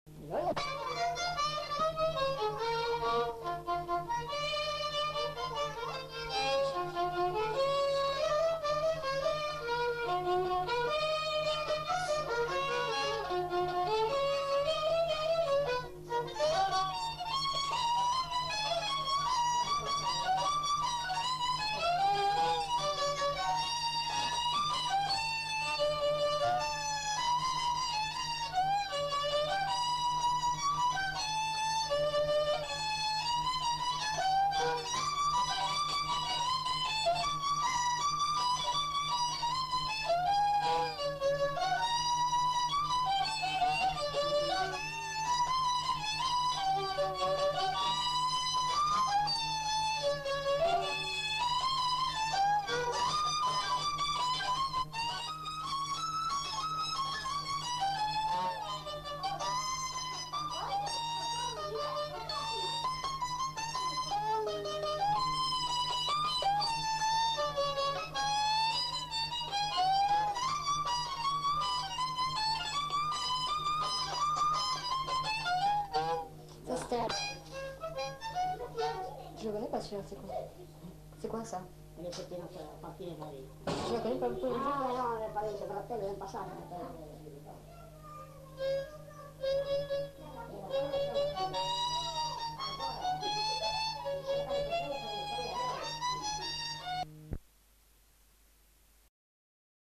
Aire culturelle : Lugues
Lieu : Saint-Michel-de-Castelnau
Genre : morceau instrumental
Instrument de musique : violon
Danse : quadrille (2e f.)
Notes consultables : 2 violons. Accélération de la bande.